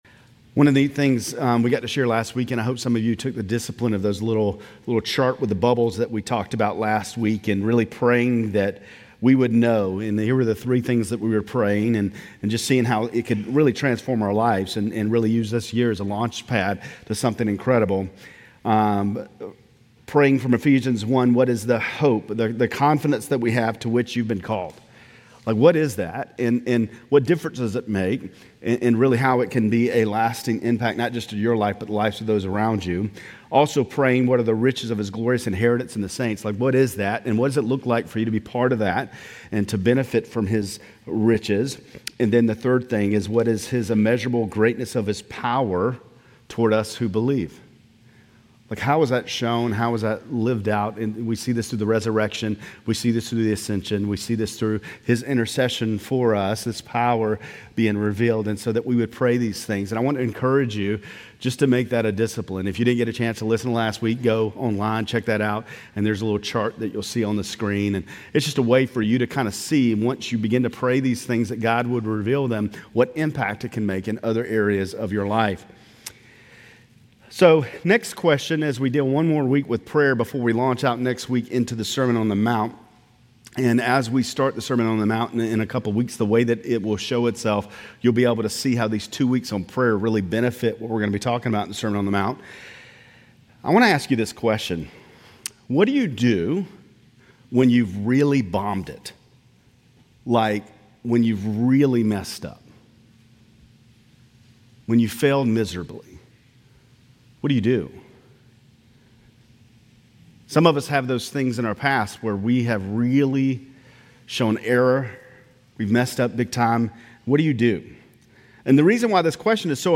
Grace Community Church Lindale Campus Sermons Jan 12 - Prayer Jan 13 2025 | 00:21:38 1x 00:00 / 00:21:38 Subscribe Share RSS Feed Share Link Embed